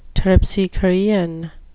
terpsichorean (turp-si-kuh-REE-uhn, turp-si-KOR-ee-uhn, -KORE-) adjective
Pronunciation: